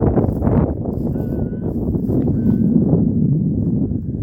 Great Egret (Ardea alba)
Location or protected area: Tafí del Valle
Detailed location: Dique La Angostura
Condition: Wild
Certainty: Recorded vocal